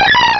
Cri de Granivol dans Pokémon Rubis et Saphir.